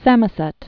(sămə-sĕt) Died c. 1653.